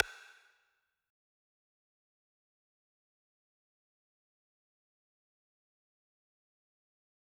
cardo rim shot .wav